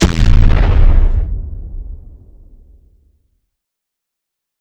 rocket_explosion.wav